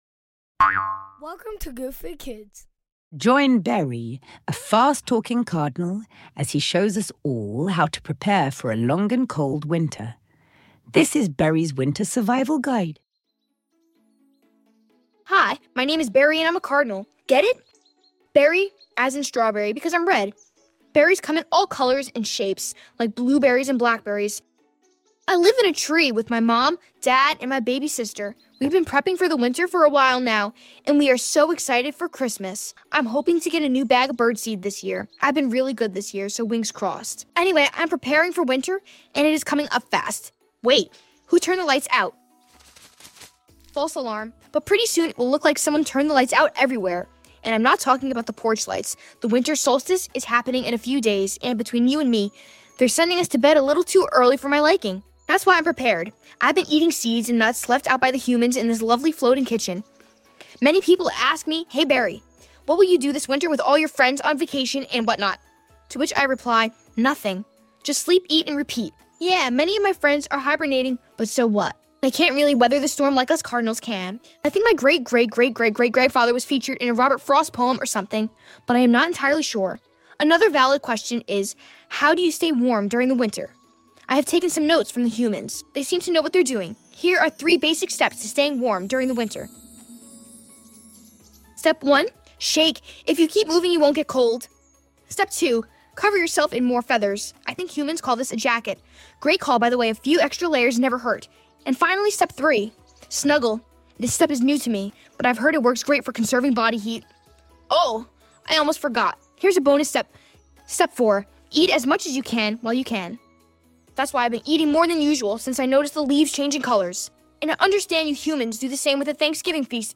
Join Barry, a fast-talking Cardinal as he shows us all how to prepare for a long and cold winter!